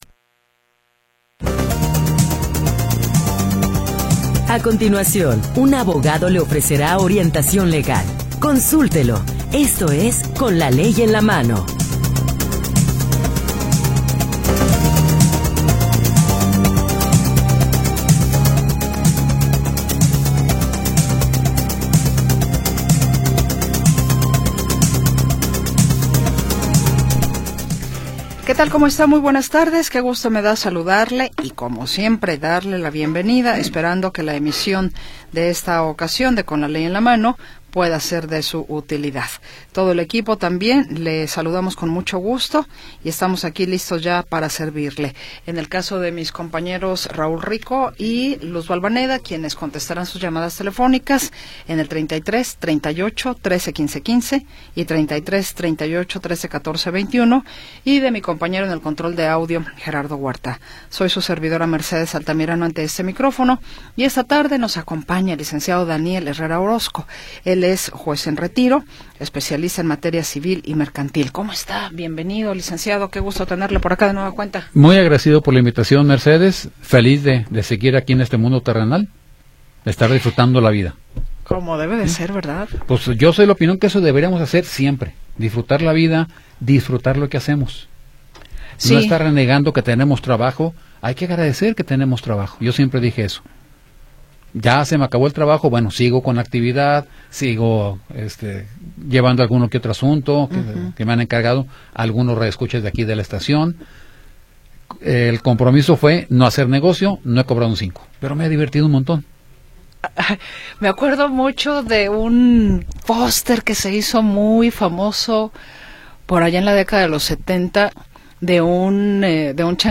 Orientación legal de jueces y abogados especialistas